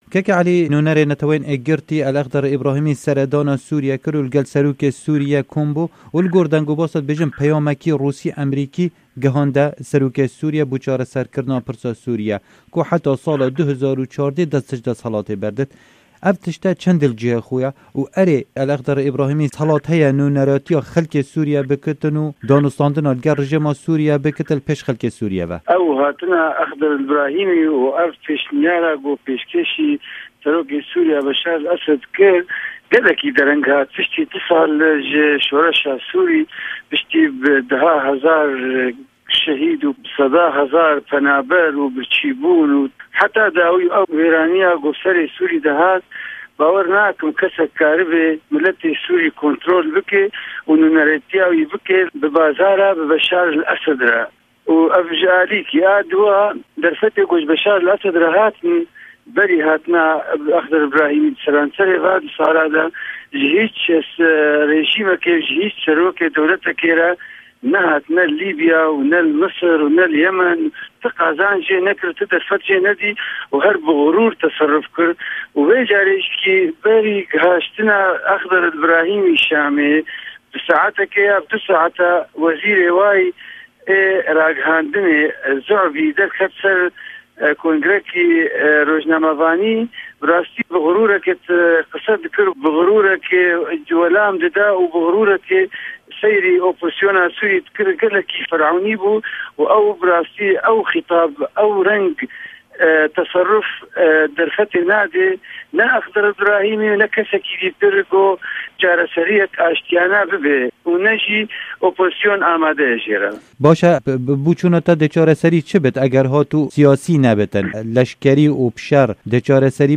Hevpeyven